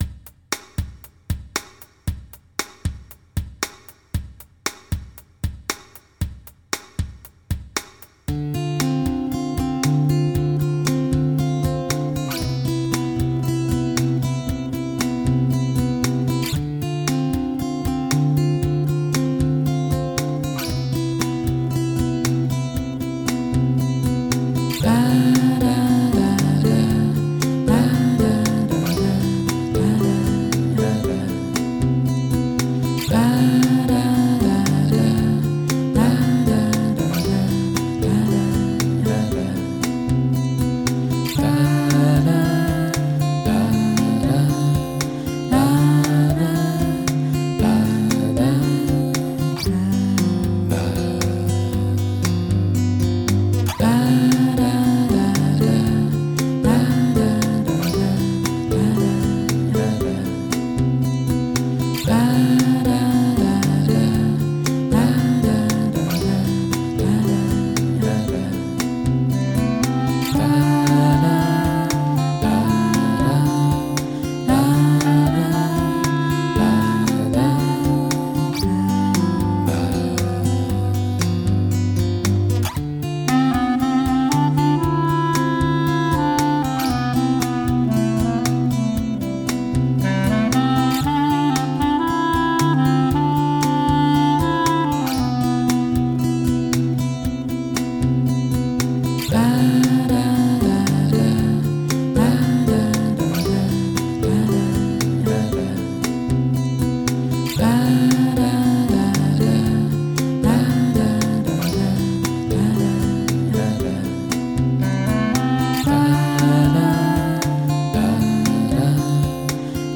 民谣吉他
电吉他
打击乐
黑管
钢琴
萌芽自花东田野的乐活心民谣 简单唱 唱得你身心松爽